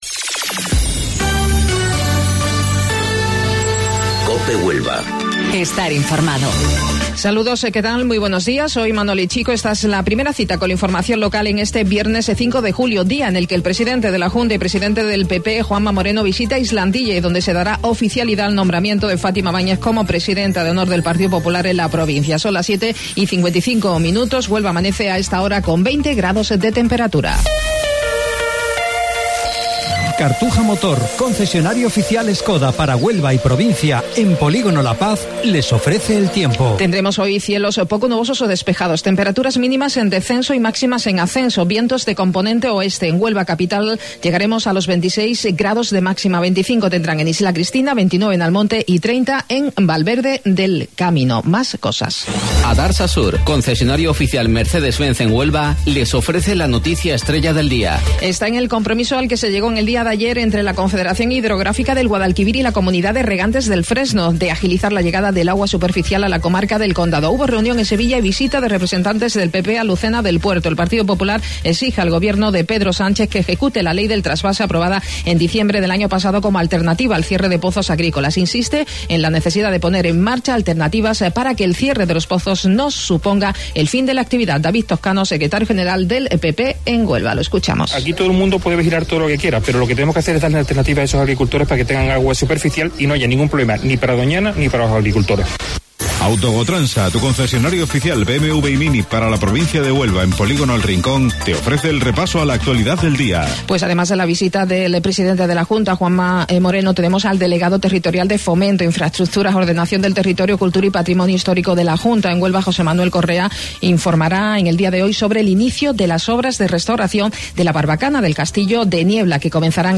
AUDIO: Informativo Local 07:55 del 5 de Julio